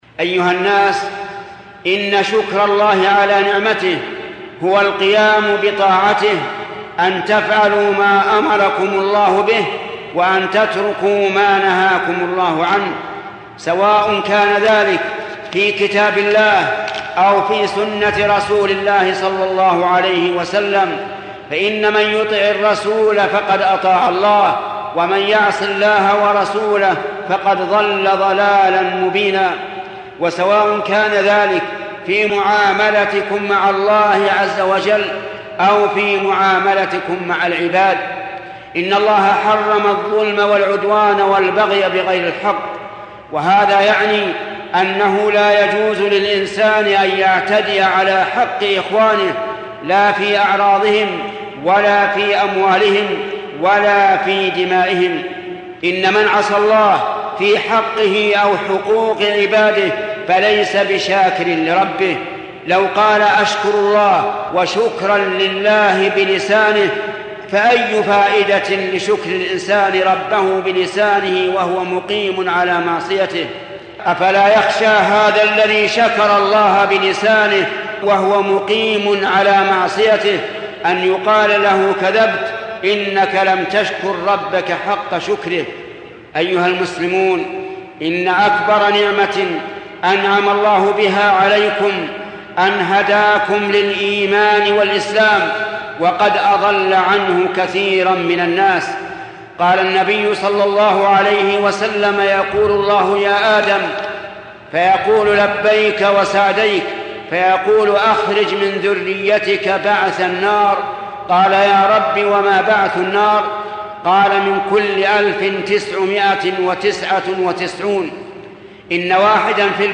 كيف نشكر الله على نعمته | الشيخ : محمد بن صالح العثيمين